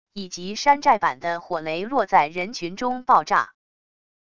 以及山寨版的火雷落在人群中爆炸wav音频